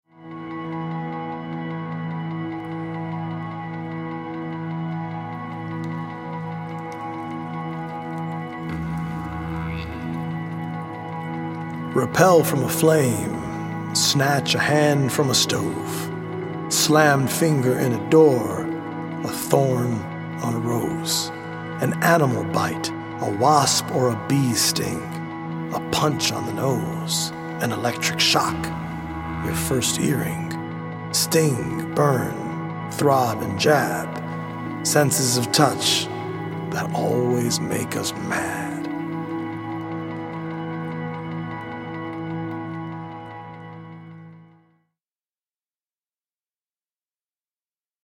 healing Solfeggio frequency music
EDM